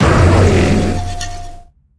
Cri de Feu-Perçant dans Pokémon HOME.